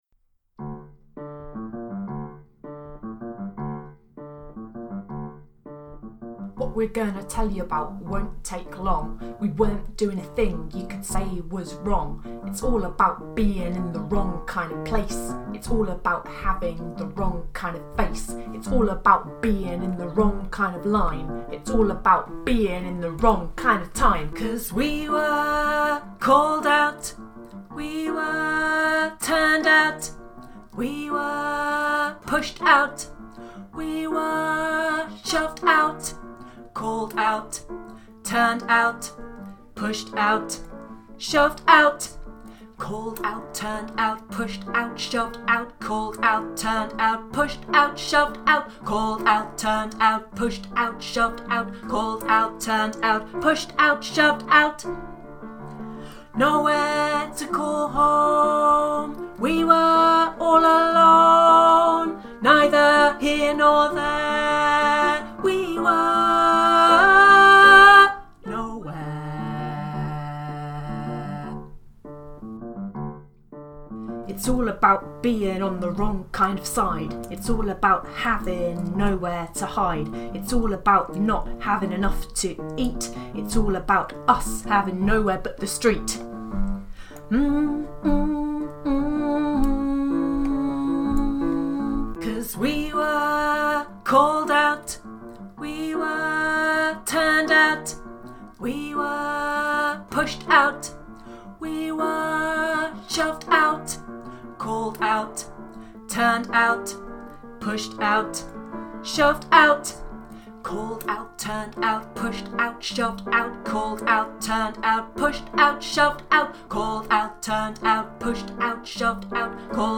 (Tenor/Low Part) Torn From Home by Michael Rosen